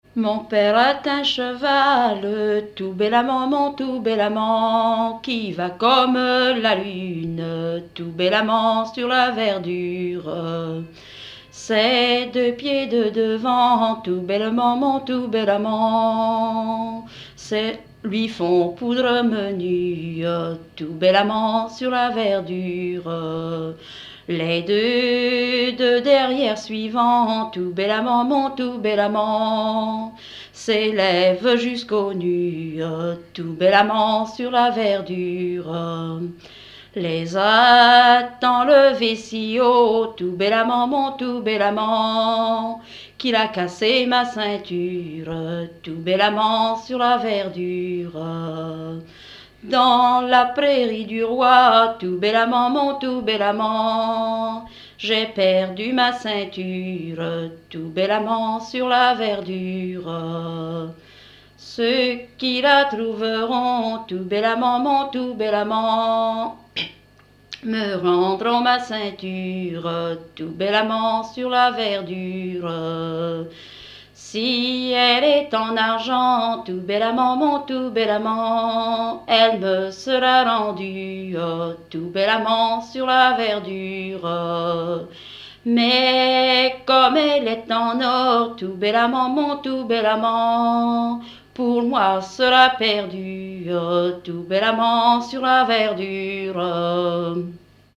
Fonction d'après l'analyste danse : ronde
Genre laisse
Catégorie Pièce musicale inédite